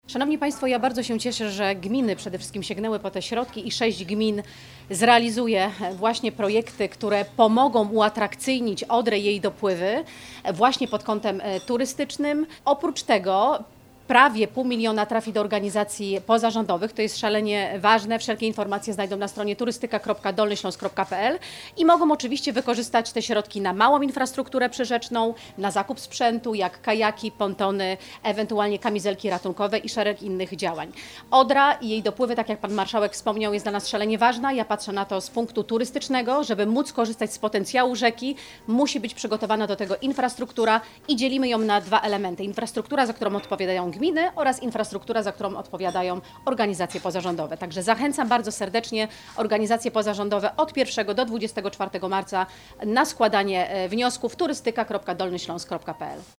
– Realizacja projektów przez gminy przyczyni się do uatrakcyjnienia Odry i jej dopływów pod kątem turystycznym – dodaje Magdalena Piasecka, Przewodnicząca Komisji Rozwoju, Turystyki, Rekreacji i Sportu w Sejmiku Województwa.